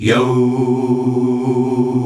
YOOOOH  C.wav